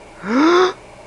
Fright Sound Effect
fright.mp3